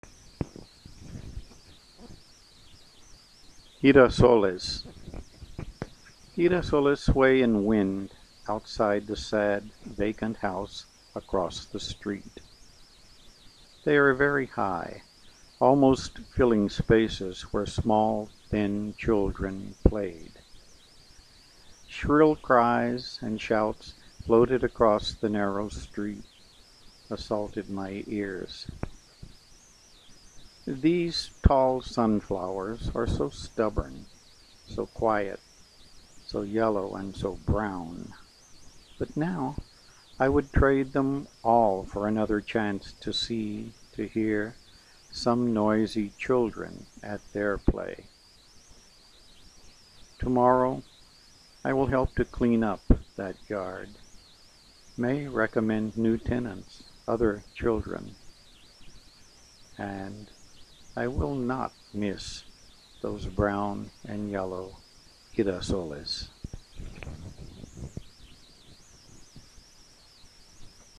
Girasoles (Spanish word) are sunflowers. Pronounced as:  hee-rah-so-lays.....